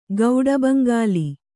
♪ gauḍa baŋgāli